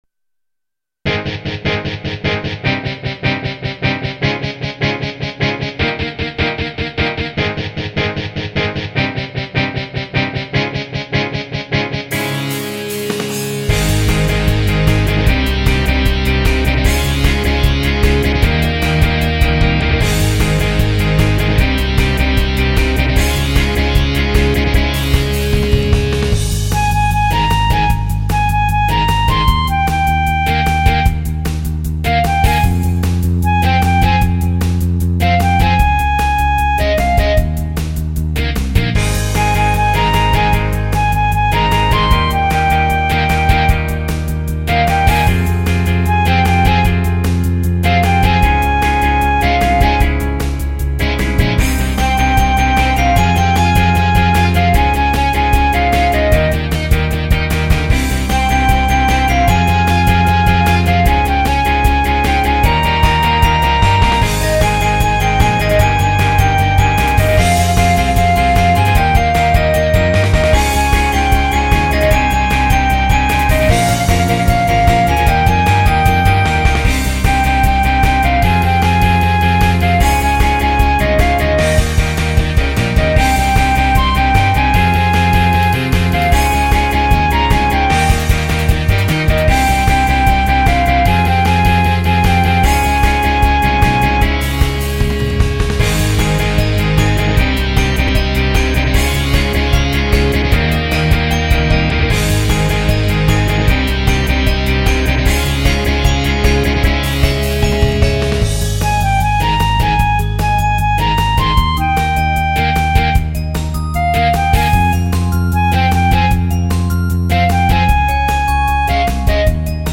ピュアピンクポップ
ギター、Music Box、チャイム